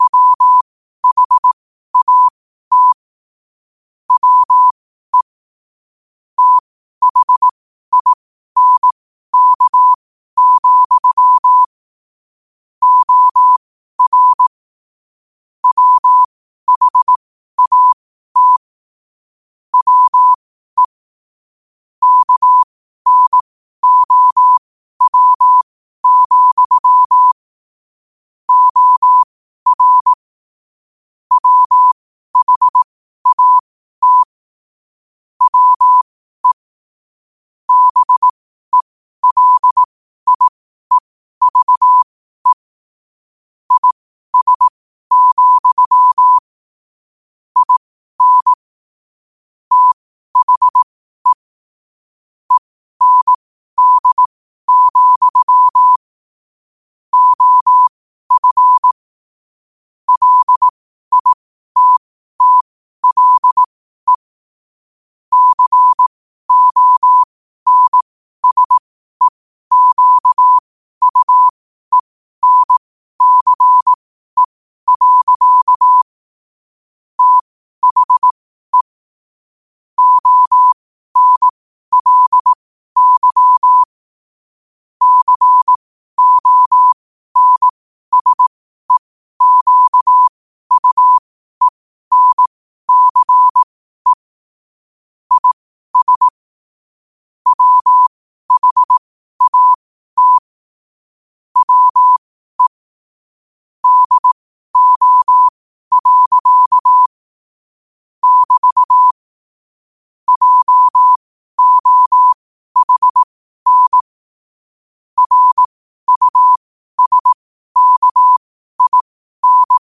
New quotes every day in morse code at 12 Words per minute.